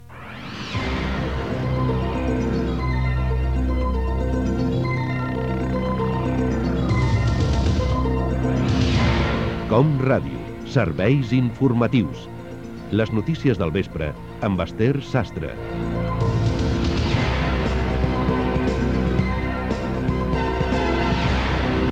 Careta de l'informatiu